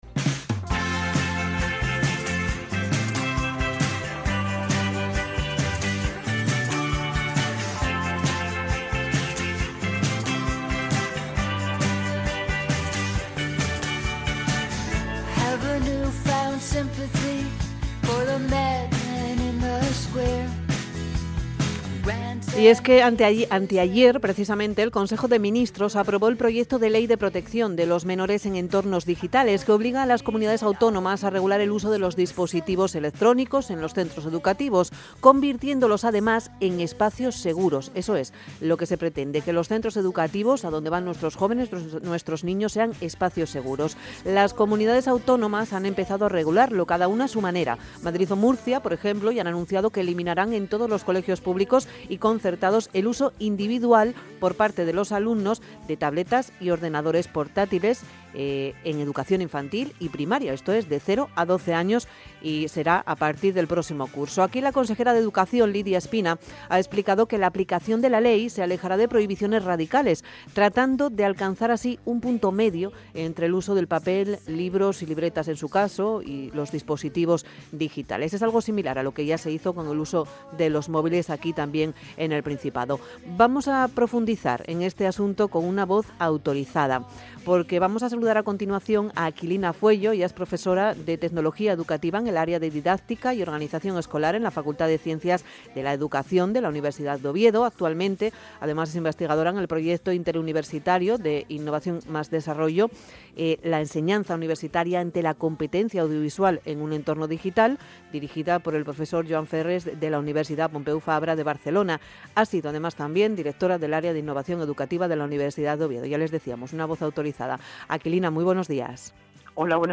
Intervención en medios sobre la Ley de Protección de los menores en los entornos digitales